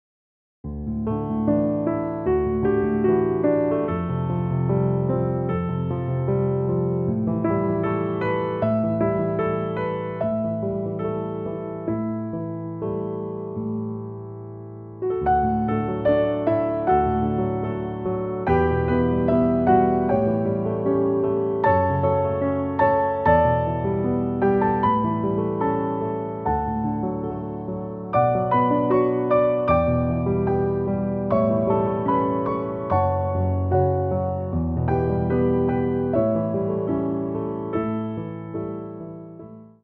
inspiring piano piece
beautiful and calming piano music